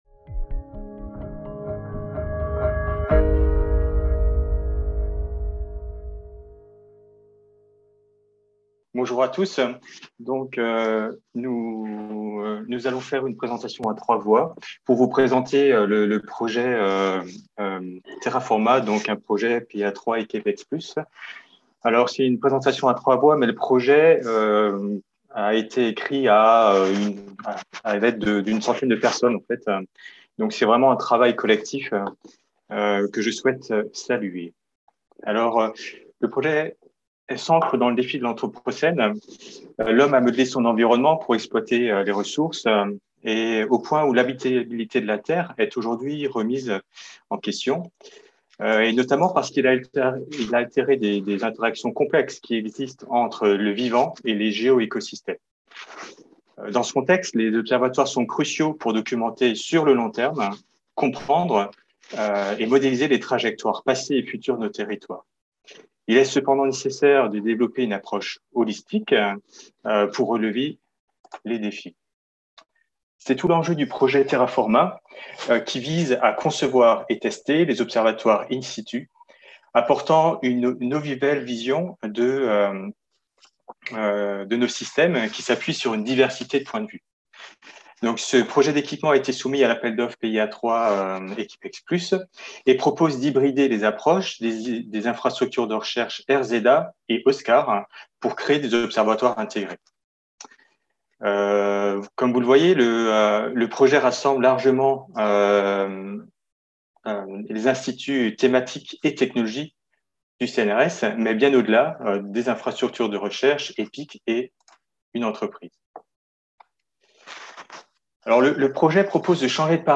Conférenceen plénière